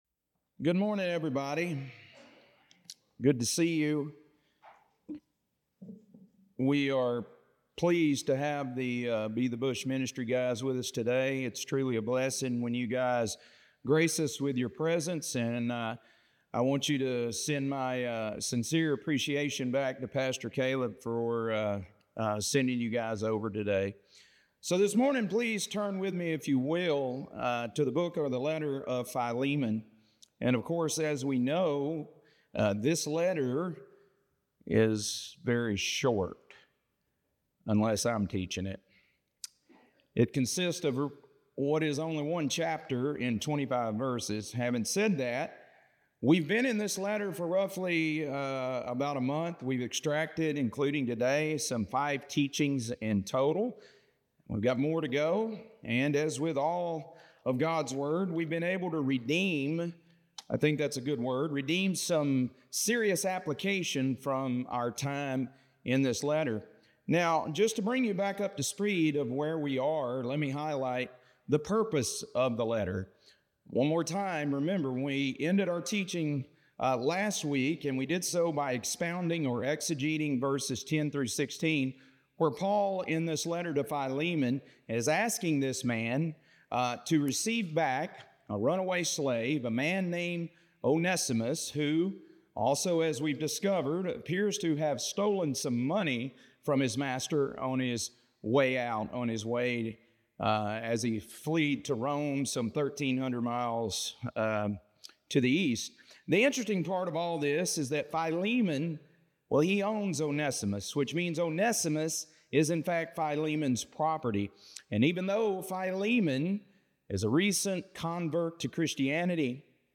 Philemon - Lesson 1E | Verse By Verse Ministry International